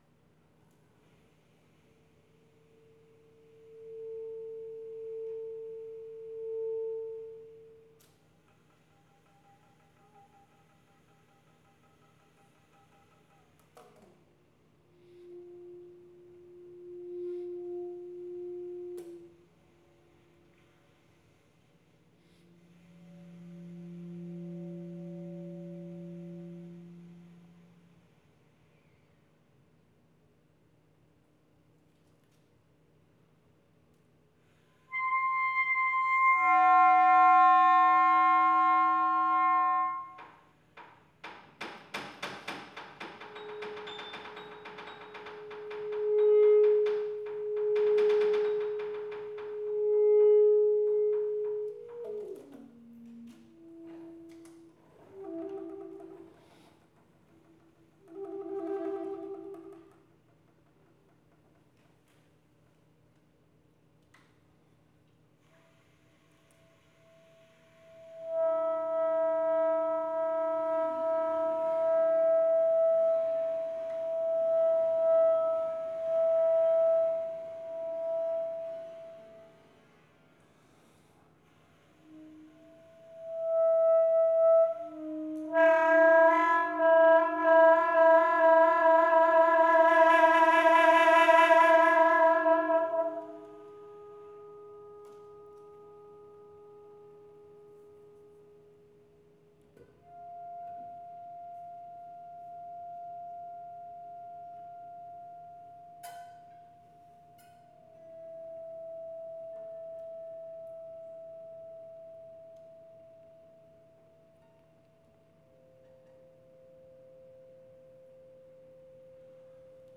Embers, my latest work for saxophone and piano premiered March 19th at the North American Saxophone Alliance region 6 conference at the University of North Florida in Jacksonville, Florida.
Here is an excerpt from their rehearsal today!